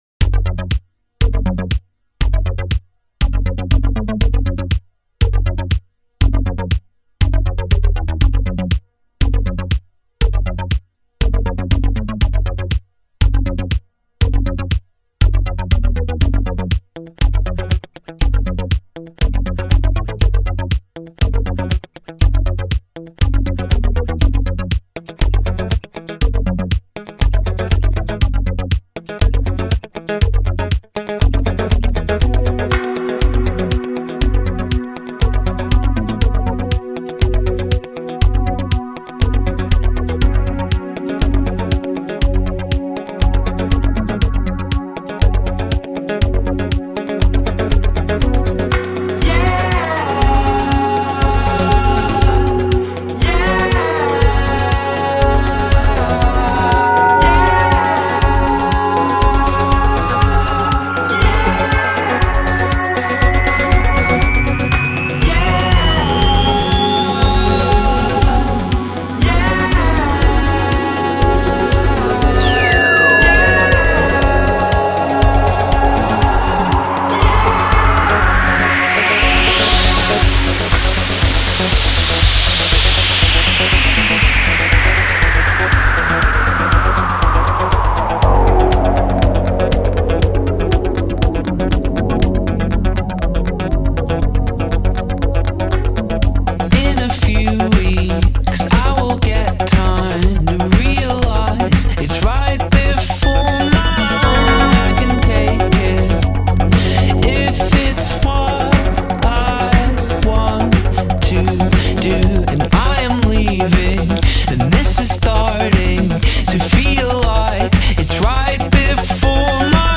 All very bright, booty-shaking, catchy, poptastical cuts.
glittery remix